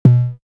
Sizzle Tone.wav